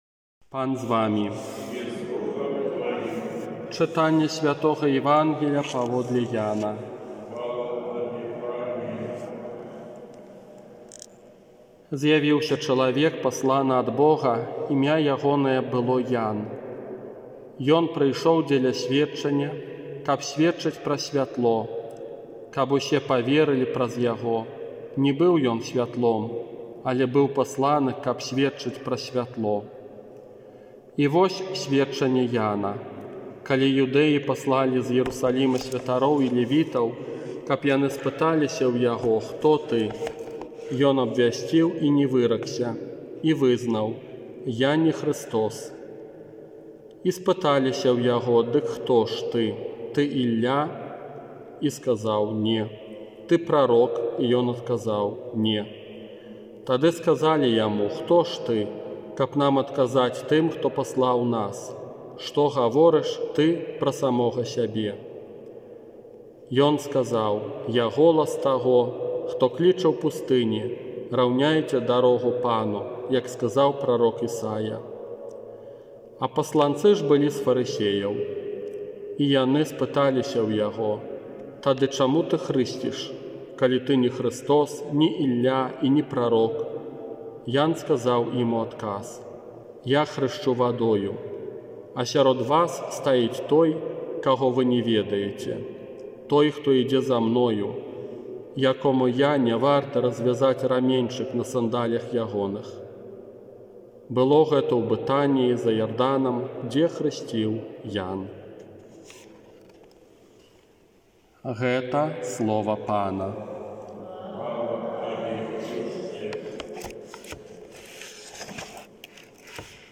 ОРША - ПАРАФІЯ СВЯТОГА ЯЗЭПА
Казанне на трэццюю нядзелю Адвэнта